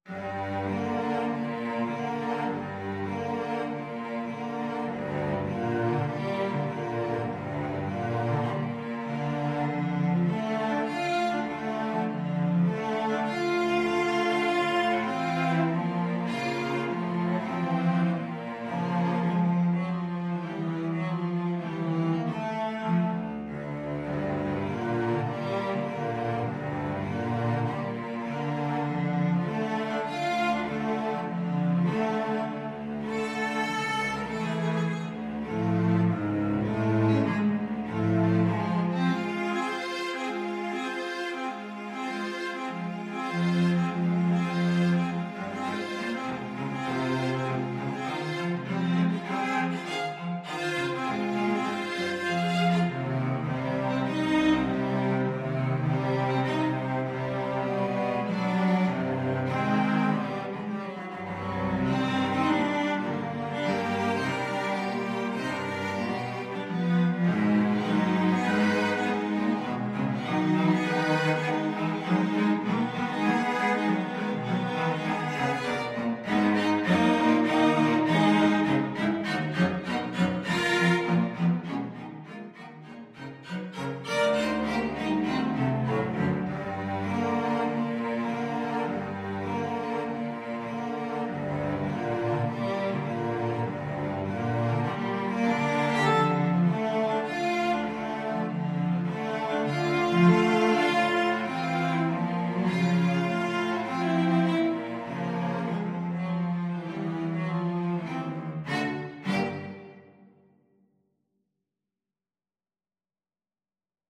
Cello Trio  (View more Advanced Cello Trio Music)
Classical (View more Classical Cello Trio Music)